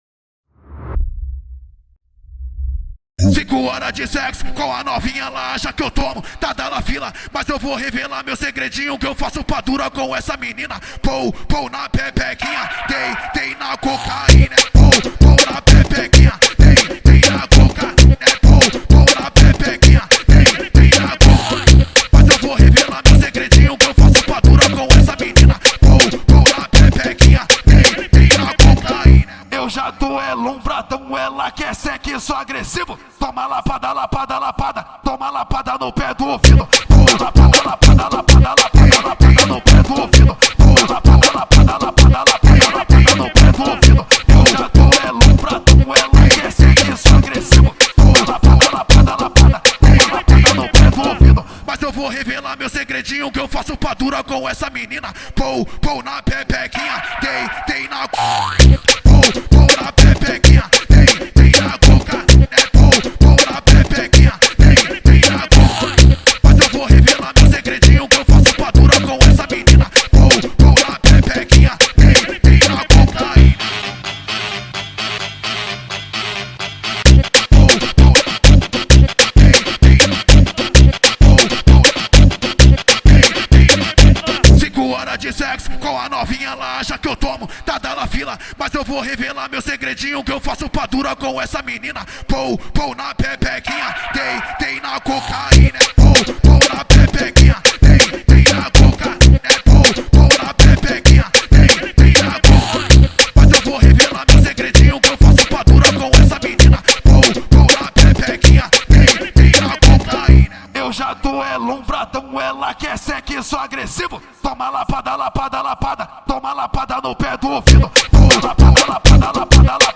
2024-11-02 22:14:55 Gênero: Funk Views